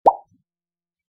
Download Pop sound effect for free.
Pop